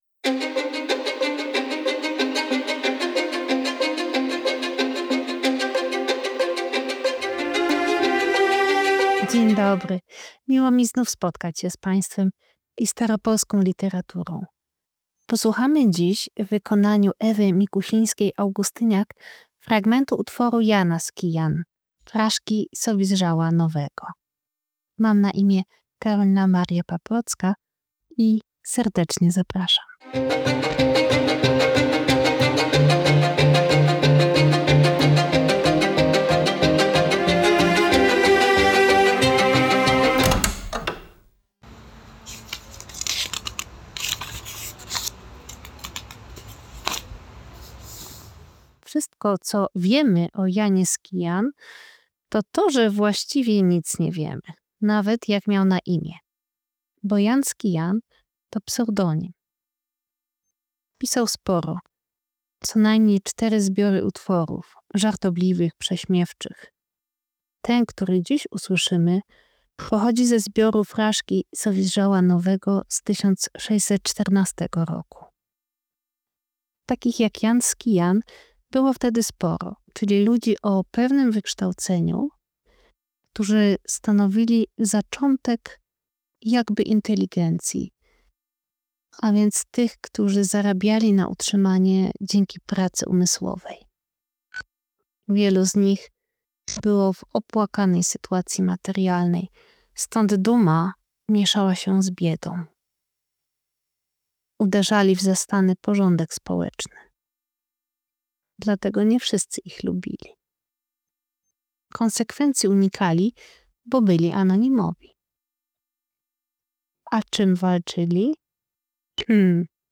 Muzyka: Music by ArtArea_Studio